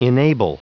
Prononciation du mot enable en anglais (fichier audio)
Prononciation du mot : enable